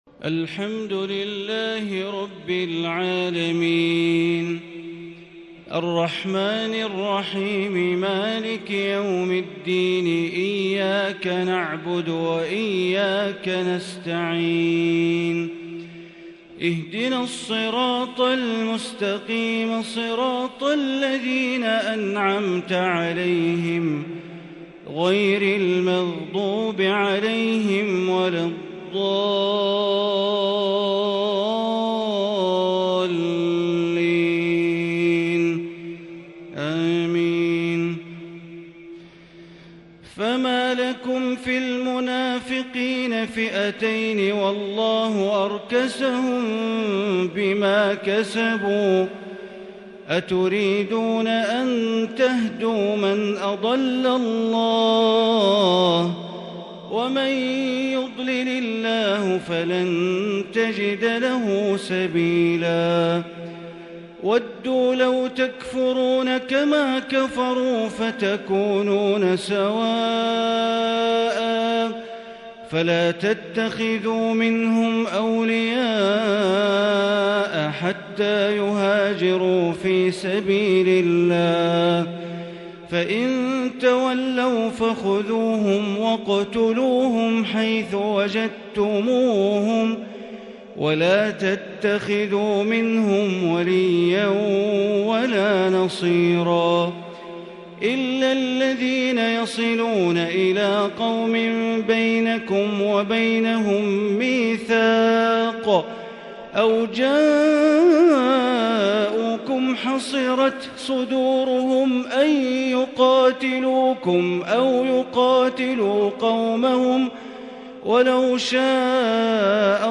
تراويح ليلة 7 رمضان 1442هـ من سورة النساء {88-134} > تراويح ١٤٤٢ > التراويح - تلاوات بندر بليلة